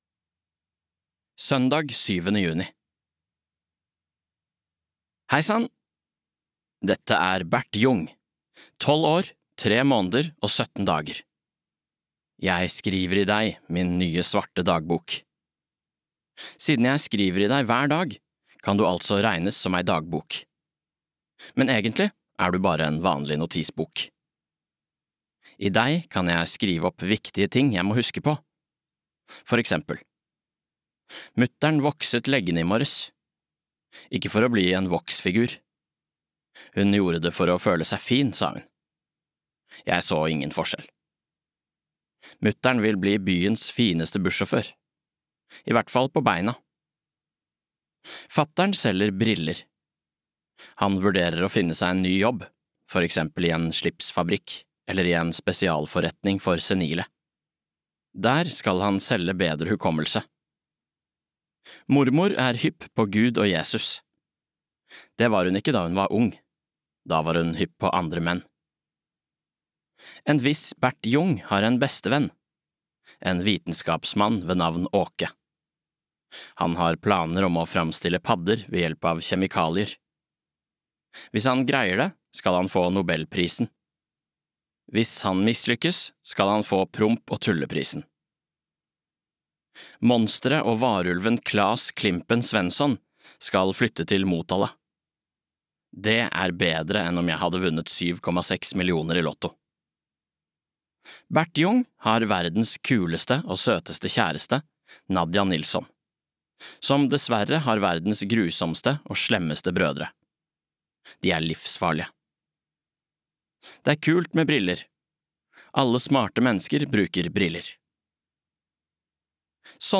Bert og brødrene (lydbok) av Anders Jacobsson